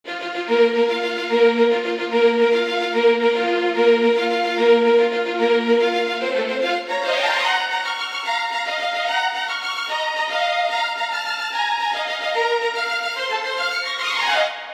No additional processing.